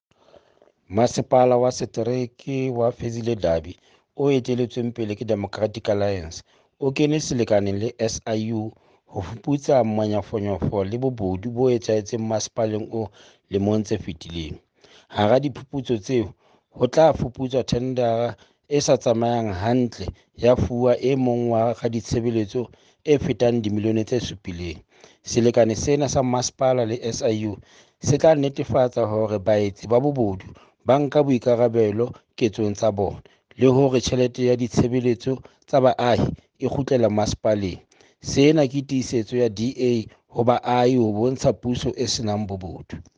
Issued by Stone Makhema – MMC Finance-Fezile Dabi District Municipality
Sesotho by Cllr Stone Makhema.